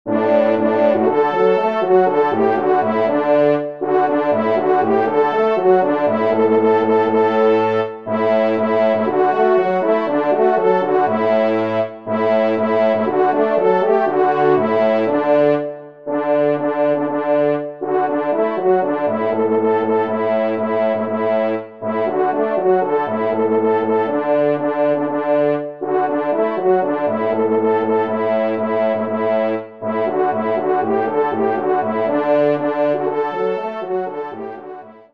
5e Trompe